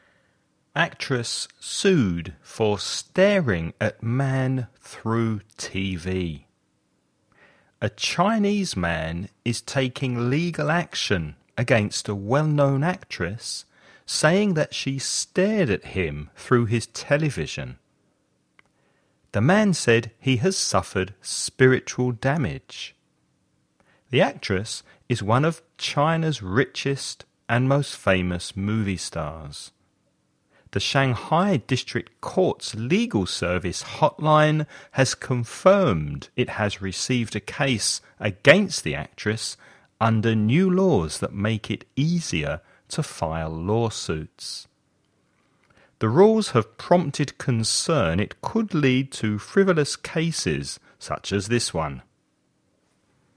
Dictados en Inglés - English dictations